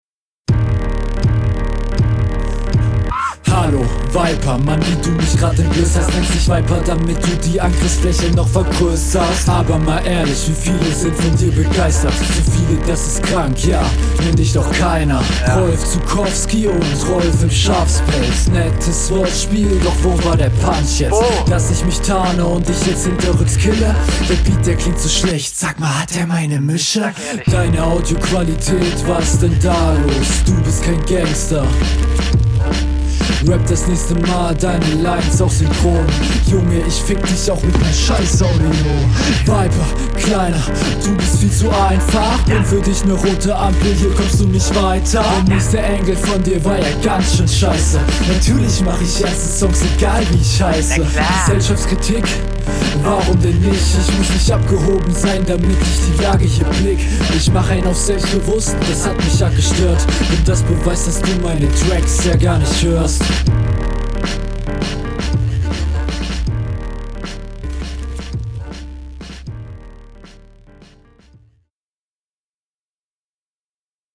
Flow: ➨ Deine Stimme passt schonmal nicht so zum Beat finde ich.
Du wirkst stimmlich etwas unsicherer, aber flowst dafür konventioneller auf dem Beat.